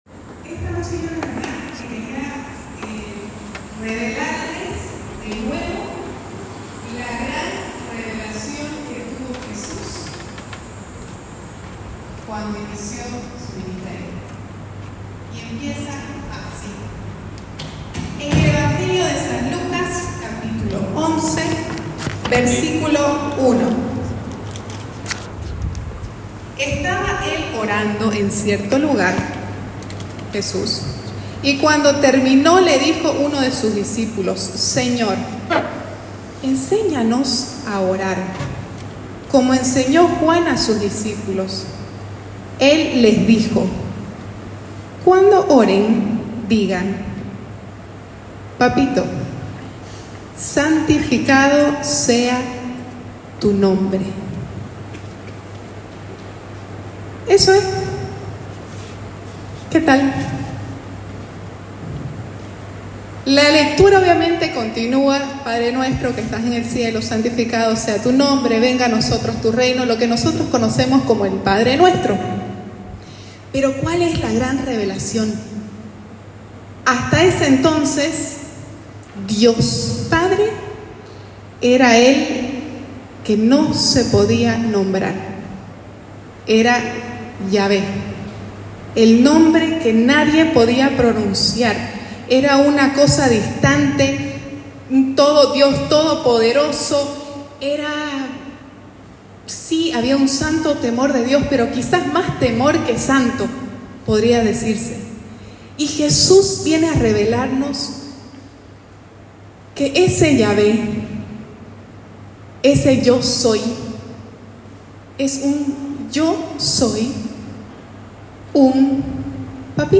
Ciclo: Seminario de vida en el Espiritu Predica